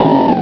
barboach.wav